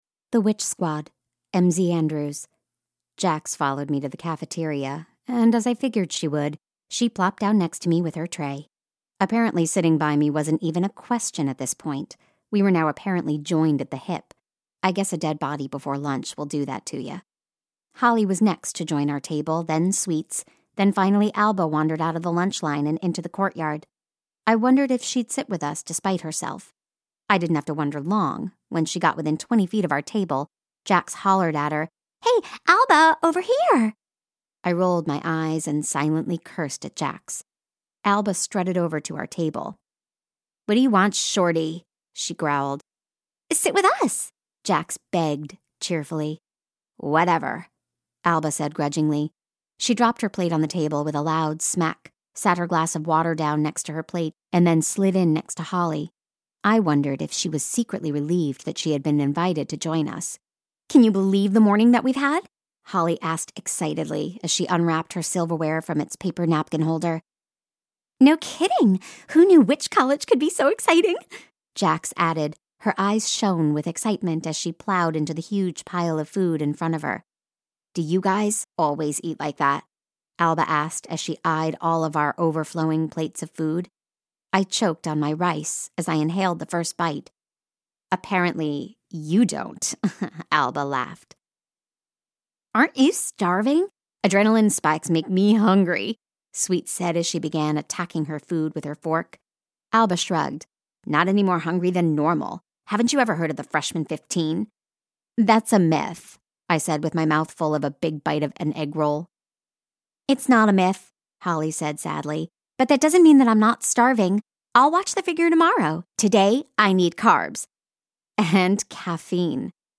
Storyteller with Characterization
The storytellers here not only deliver an engaging narrative, they can also invoke a variety of characters bringing an audio book to life.
Accent: American